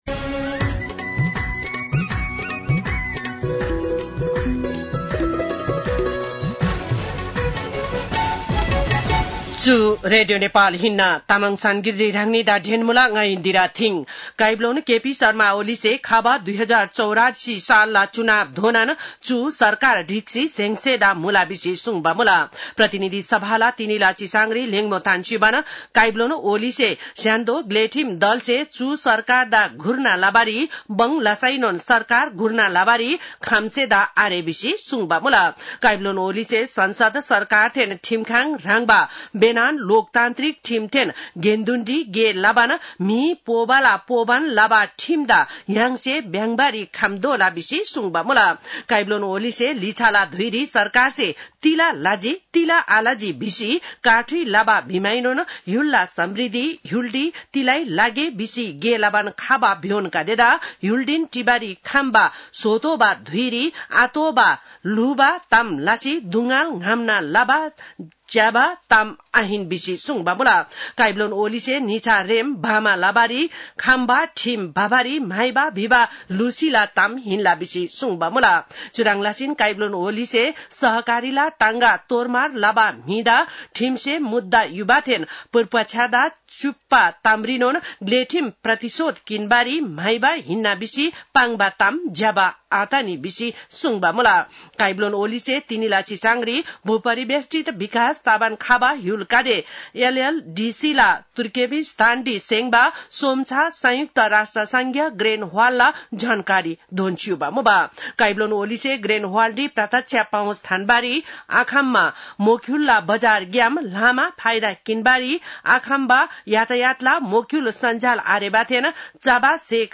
तामाङ भाषाको समाचार : २८ साउन , २०८२